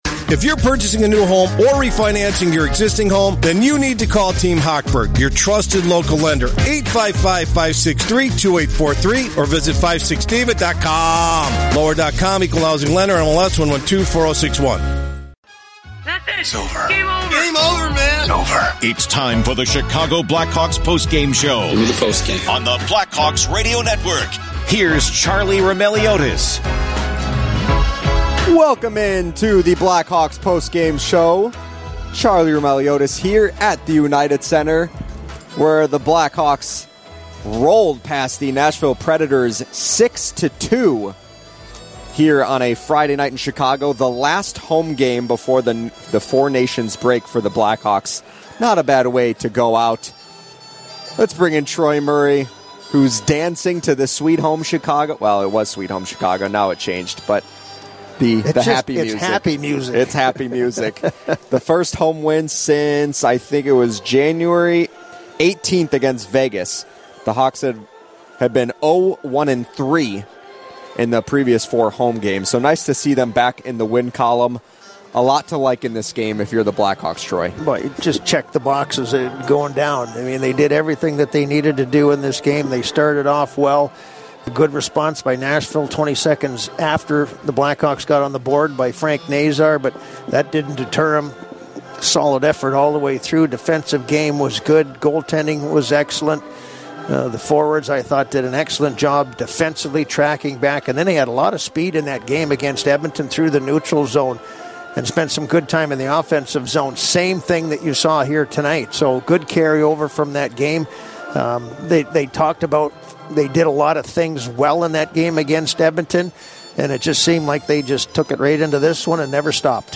Troy Murray joins the discussion to talk about Chicago’s effort in the game with Donato, Nazar, and Bedard leading the way. The two also talk about the anticipated 4 Nations Face-Off. Later, we hear from Seth Jones, Frank Nazar, Ryan Donato, and Interim Head Coach Anders Sorenson.